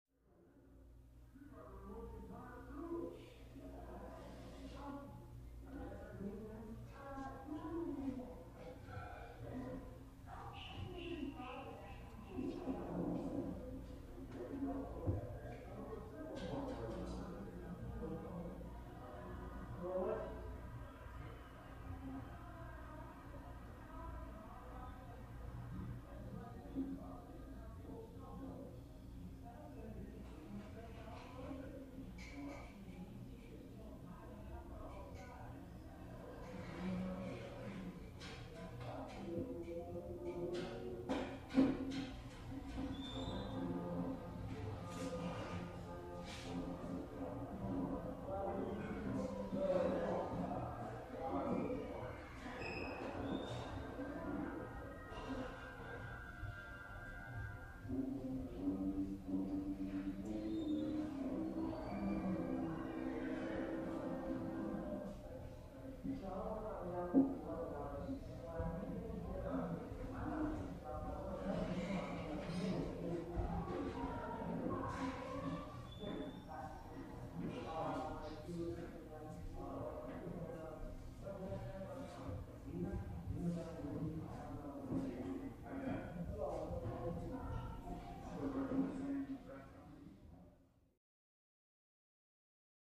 Interior Building Ambience, W Tv And Voices Heard Through Walls, Light Movement, Doors Oc.